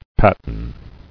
[pat·in]